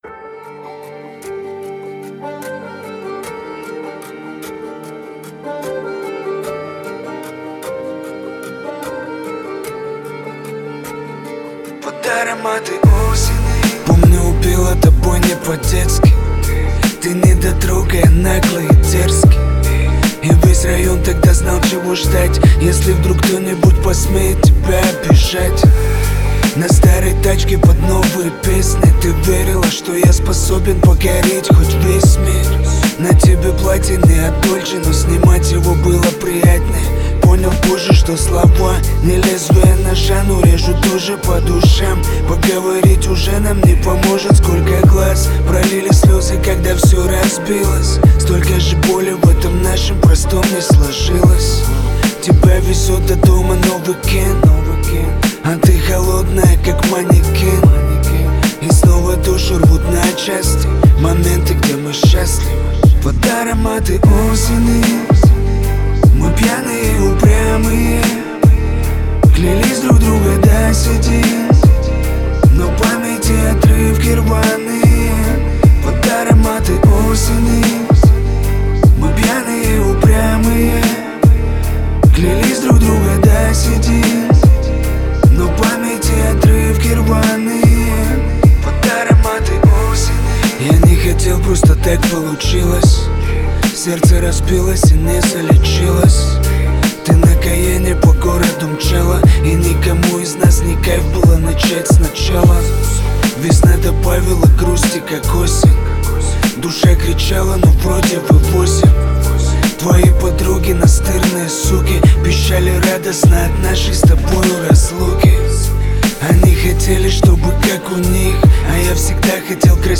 Жанр: Рэп